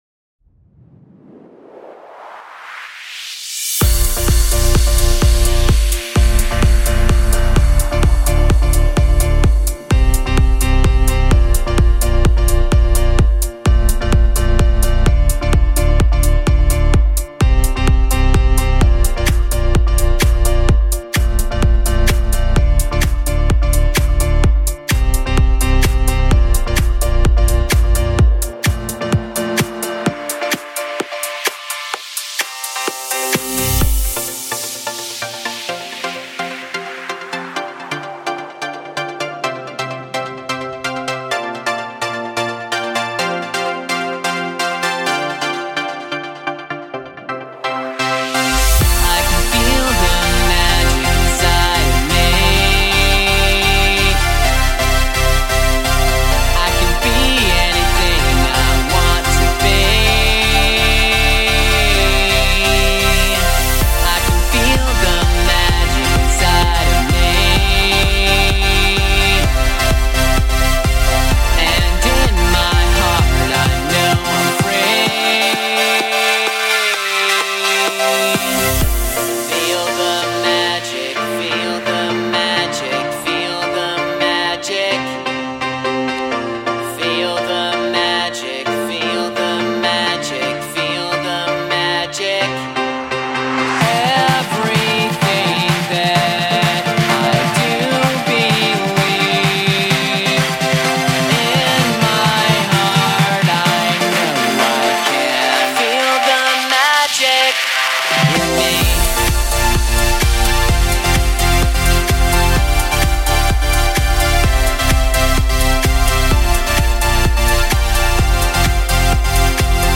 Progressive House | 128 BPM | F